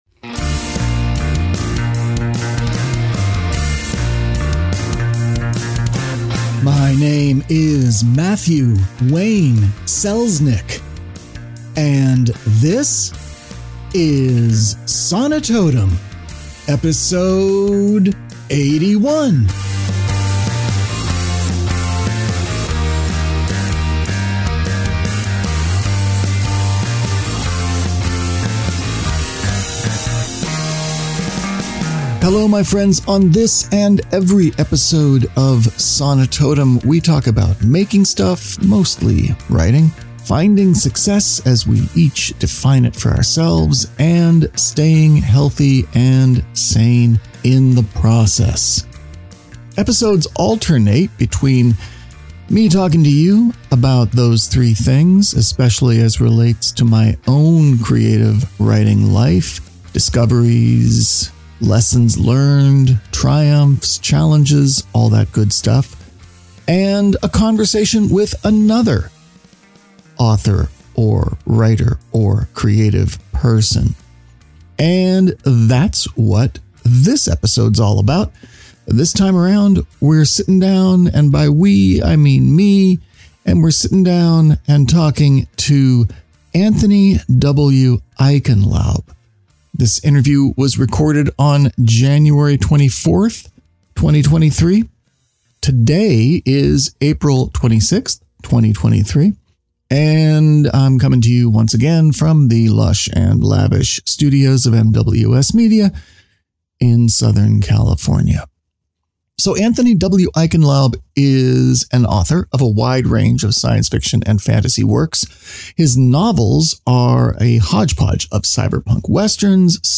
Sonitotum 081: In Conversation